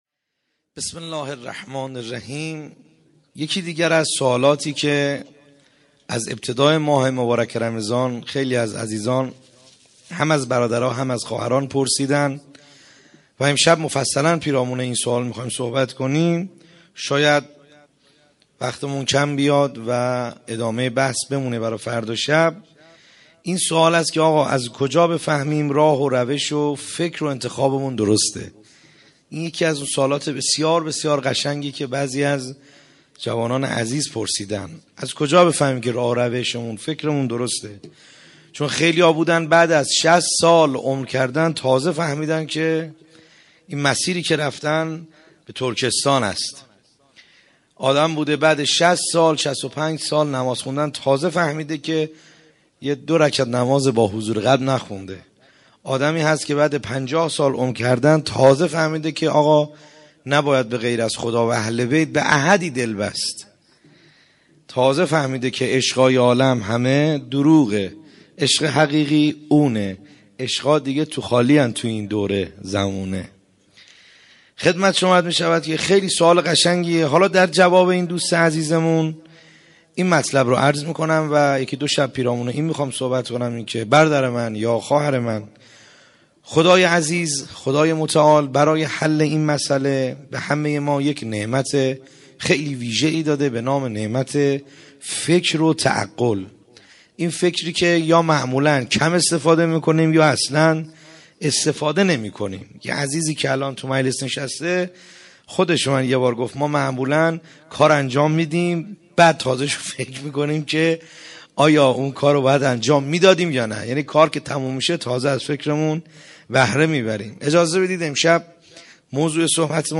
خیمه گاه - بیرق معظم محبین حضرت صاحب الزمان(عج) - سخنرانی | شب بیست و هفتم